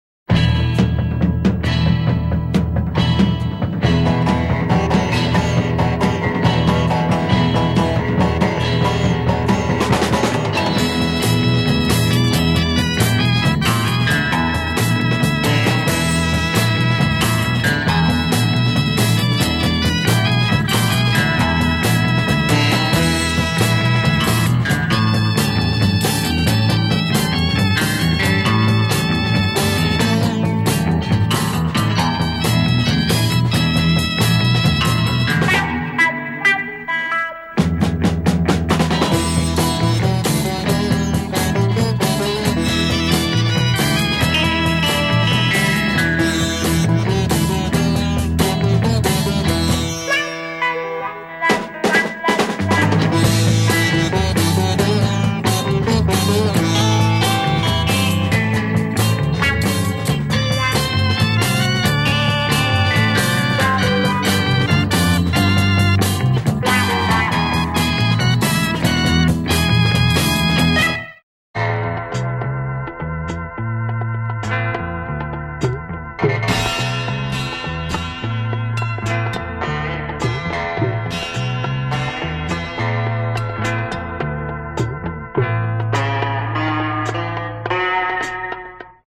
Superb exotic sitar groove
Cool beats and samples too.
This is late 60’s / early 70’s library music material !